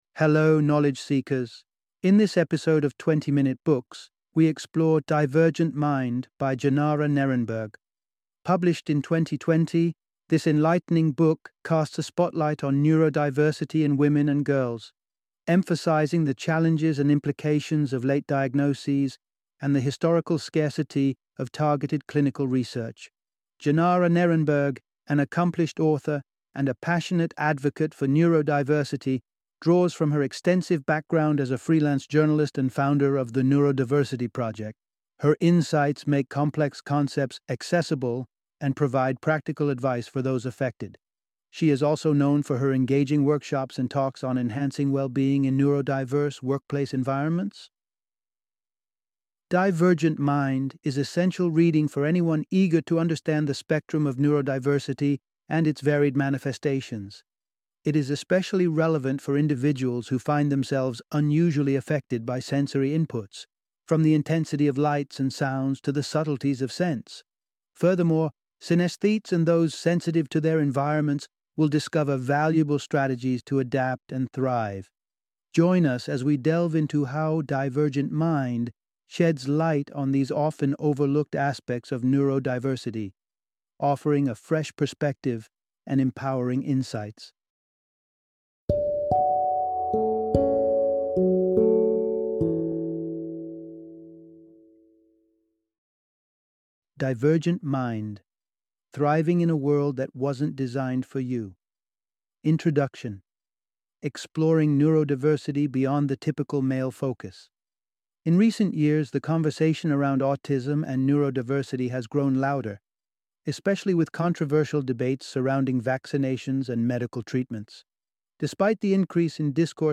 Divergent Mind - Audiobook Summary